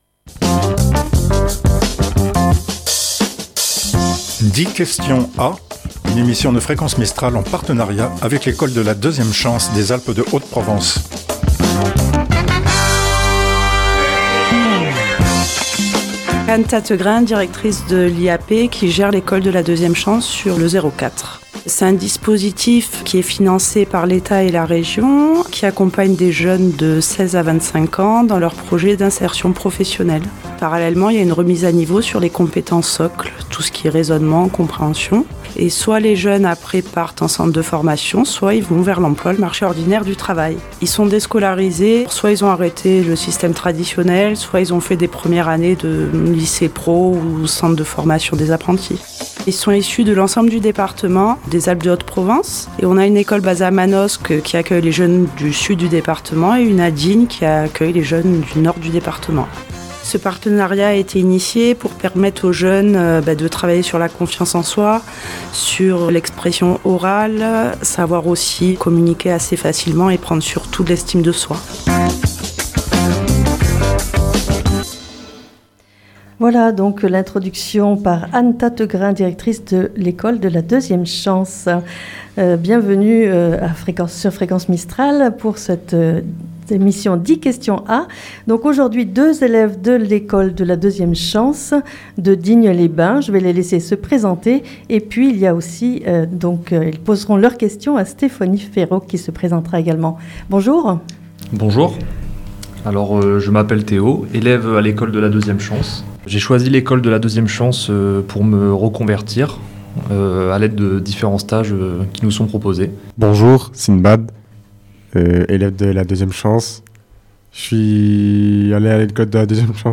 Une émission co-réalisée et co-produite par Fréquence Mistral en partenariat avec l'école de la deuxième chance de Digne les bains et de Manosque.